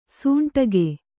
♪ suñṭage